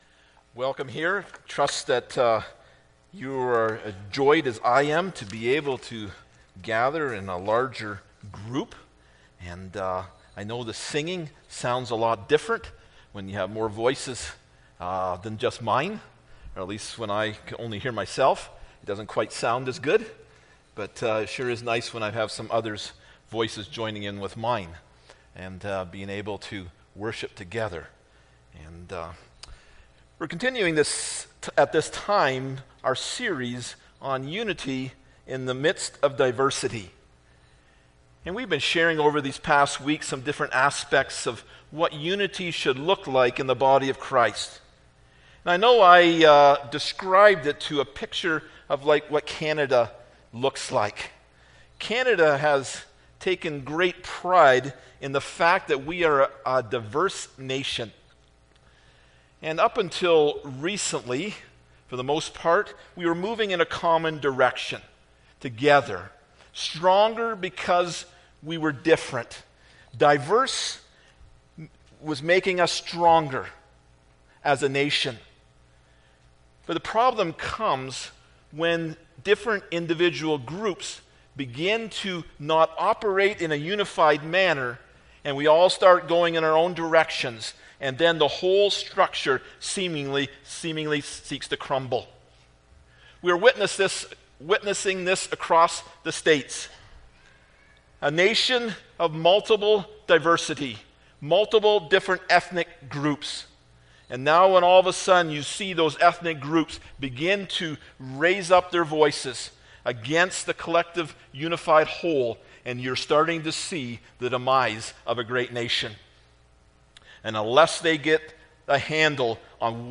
1 Cor 12:18-26 Service Type: Sunday Morning Bible Text